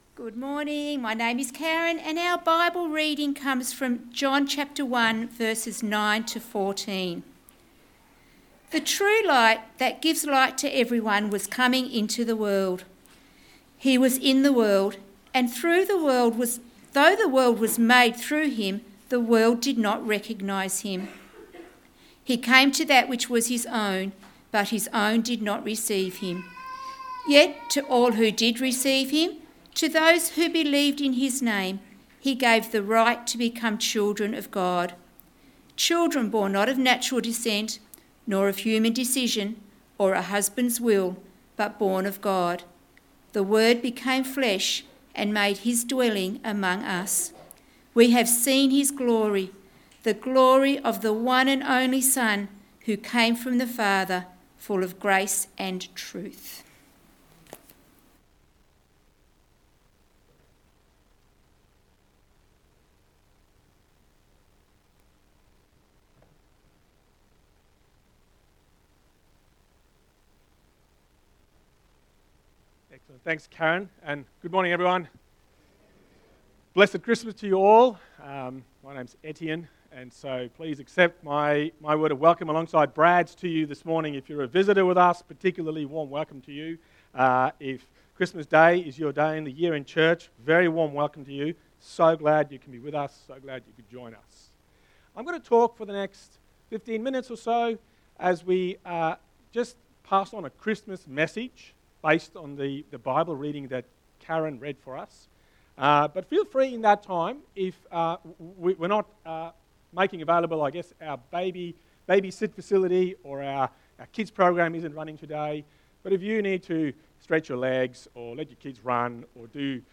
Text: John 1: 9-14 Sermon